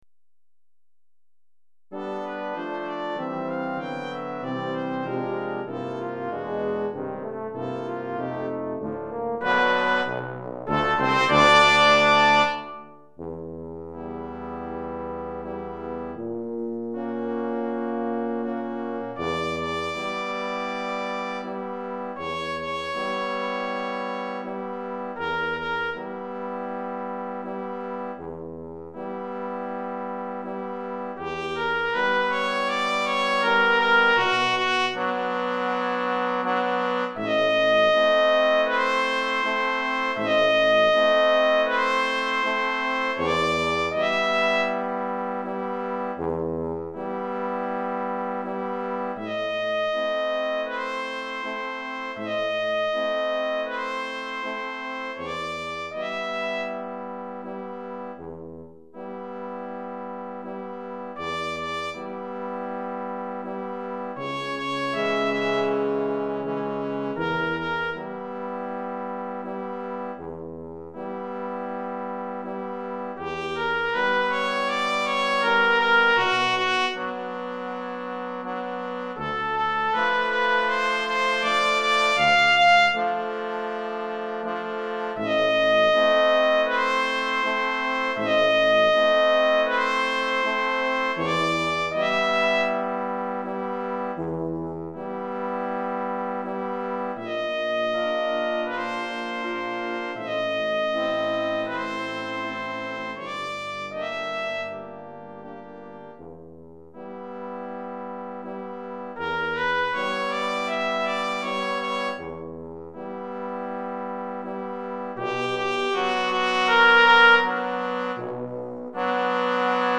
Répertoire pour Musique de chambre